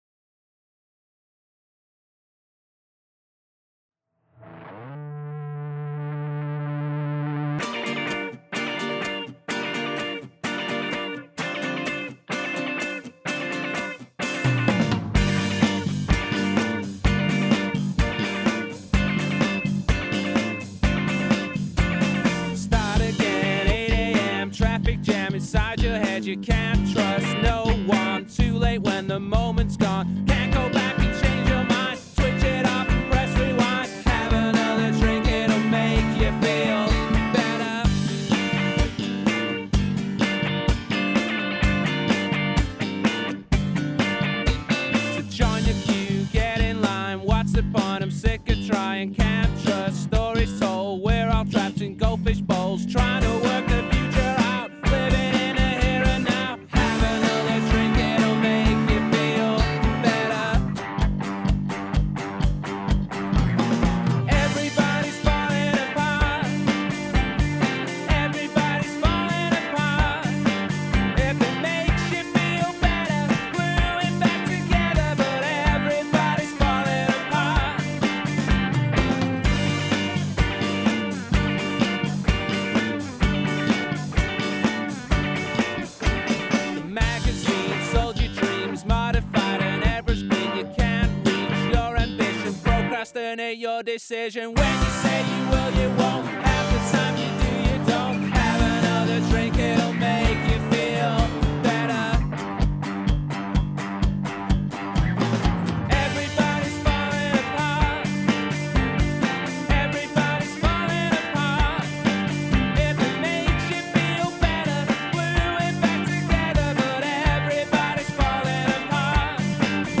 Lead Mix Engineer